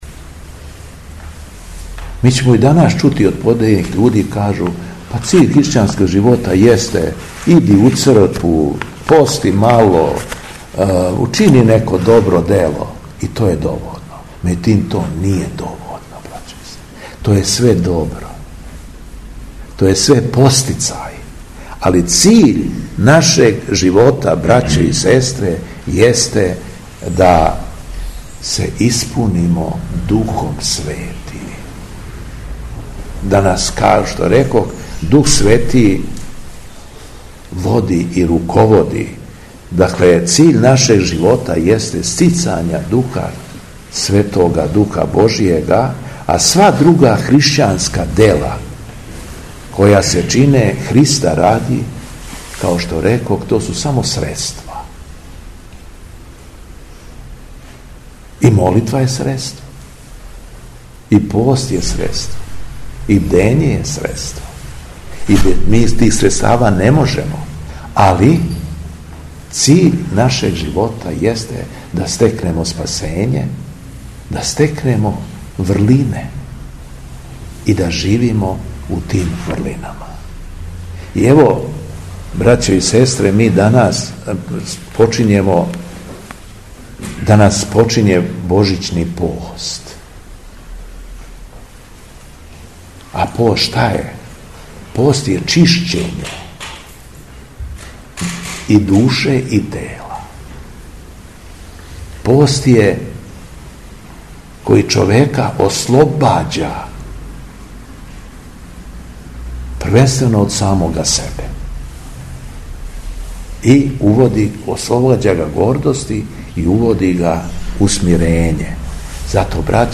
ГОСТОПРИМНИЦА – Одломак из беседе Митрополита шумадијскоког Јована о Божићном посту